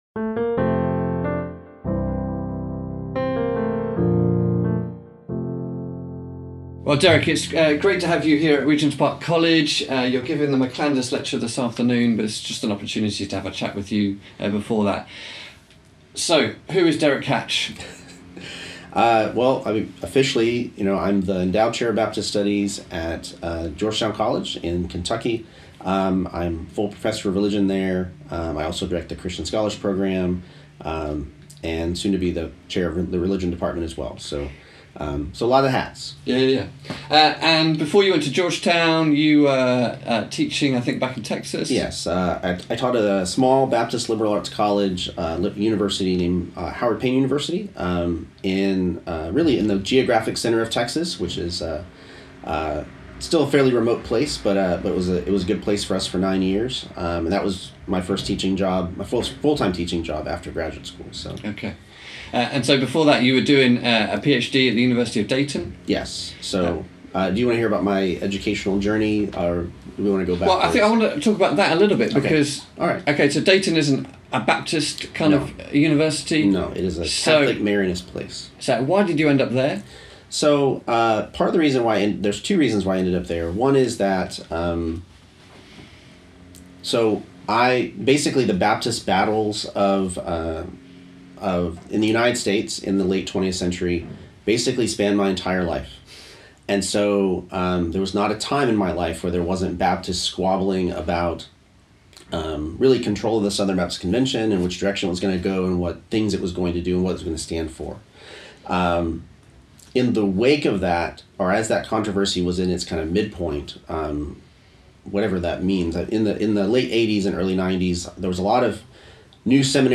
A ‘podcast’ interview